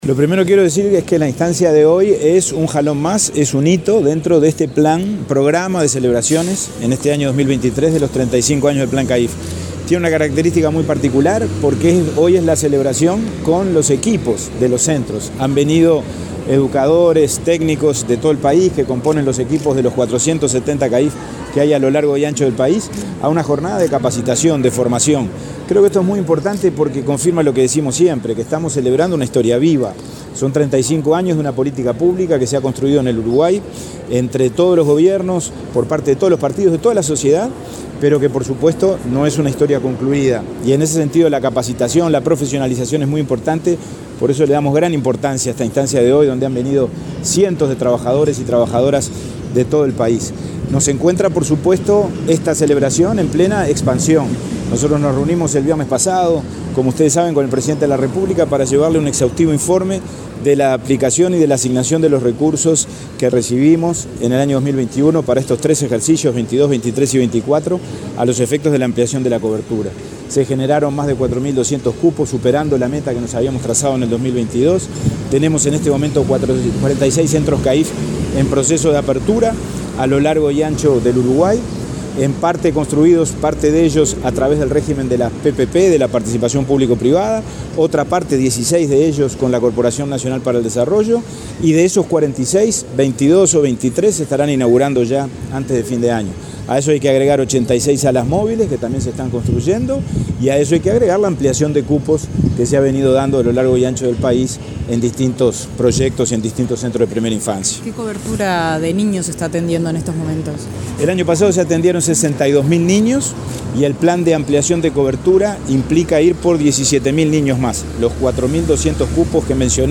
Declaraciones del presidente de INAU, Pablo Abdala
Declaraciones del presidente de INAU, Pablo Abdala 14/08/2023 Compartir Facebook X Copiar enlace WhatsApp LinkedIn Este lunes 14 en Montevideo, el presidente de Instituto del Niño y el Adolescente del Uruguay (INAU), Pablo Abdala, dialogó con la prensa antes de participar en un seminario por los 35 años del plan CAIF.